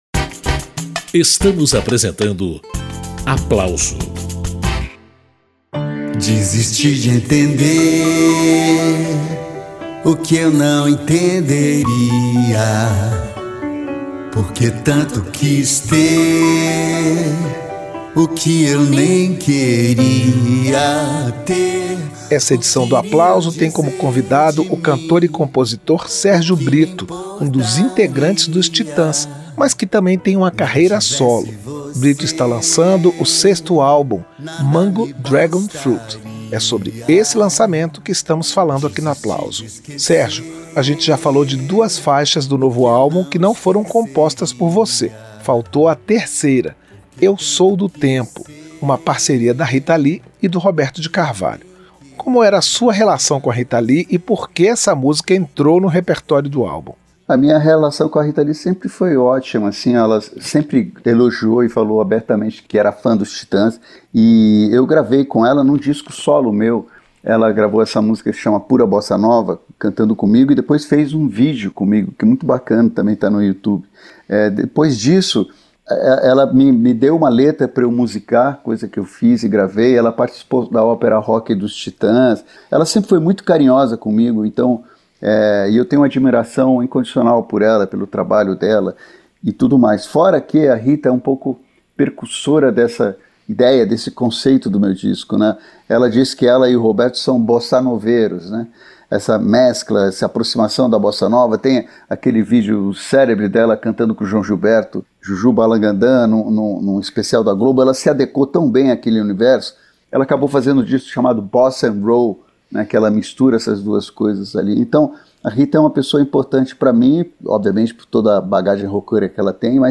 O disco tem composições próprias e regravações – entre elas a de um clássico da Bossa Nova e de uma música composta em 1924. Nesta edição do Aplauso, Sérgio Britto fala do processo de criação do novo trabalho e dos planos de levar esse repertório para os palcos.